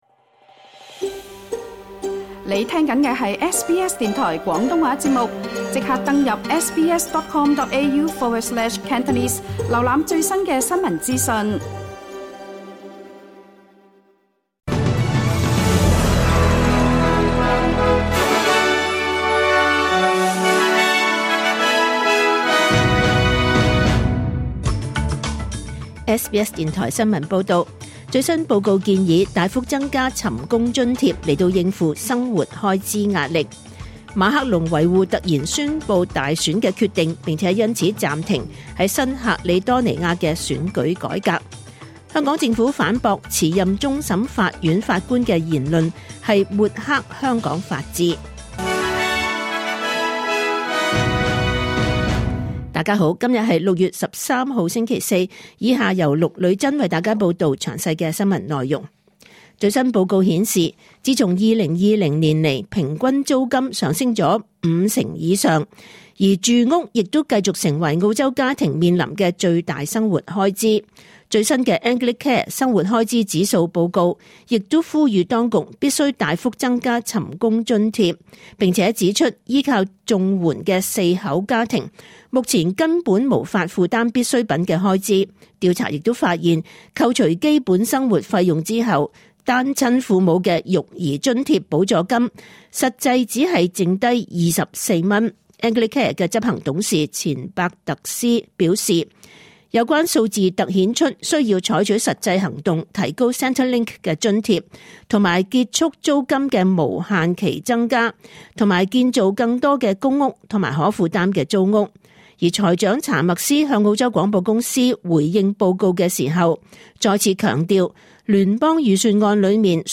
2024 年 6 月13 日 SBS 廣東話節目詳盡早晨新聞報道。